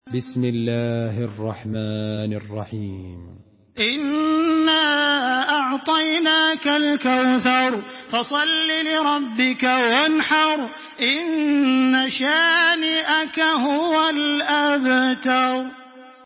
تحميل سورة الكوثر بصوت تراويح الحرم المكي 1426